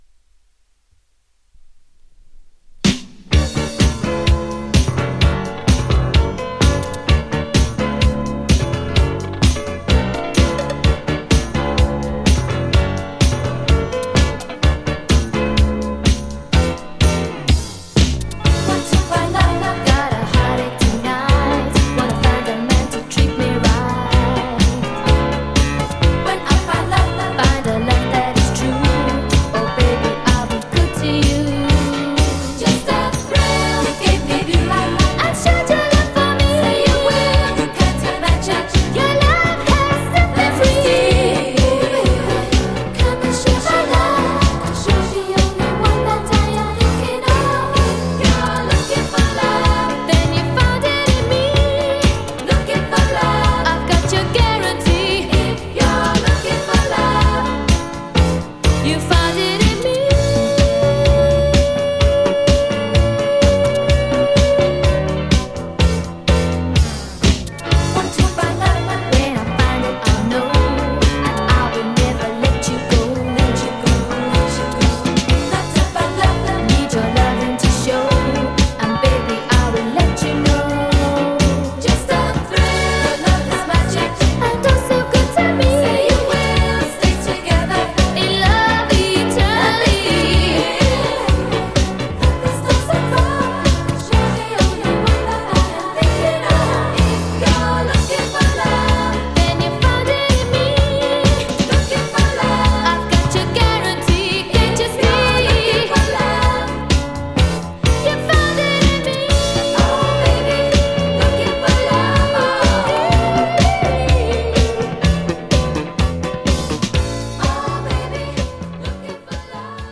女性ボーカルがポップな80'Sブギー・ディスコが12インチで正規再発！！
←2分割録音しています。
ジャンル(スタイル) DISCO